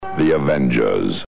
You know you're listening to a Springbok Radio programme by the distinctive voices of the announcers that can be heard at the top and tail of each episode...
This is the title of the series, spoken over the theme music. In all instances, this was the voice of Tony Jay, the original adaptor of scripts and director of the programmes.